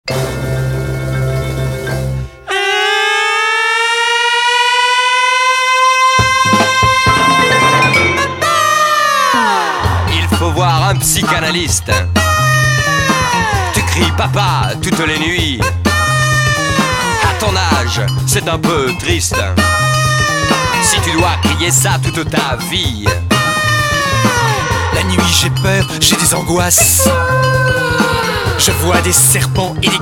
Pop psychédélique